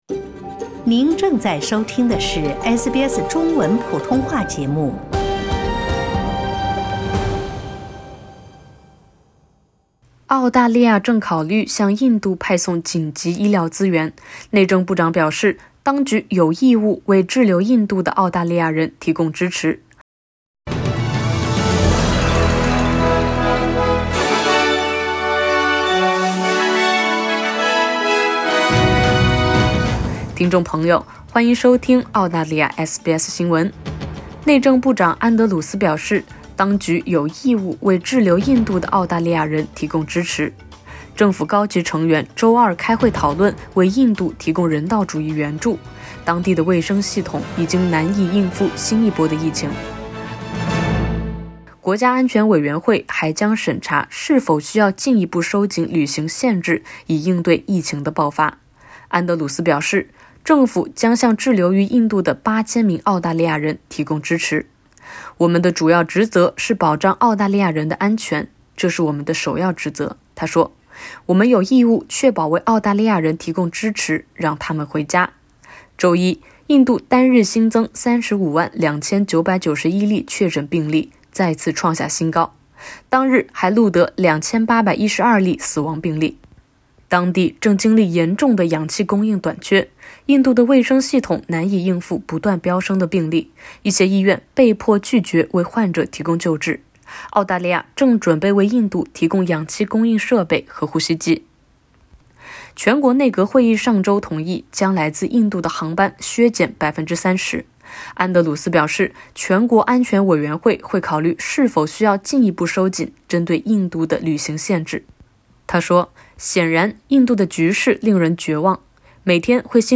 联邦政府表示将向滞留于印度的澳洲人提供支持。（点击上图收听报道）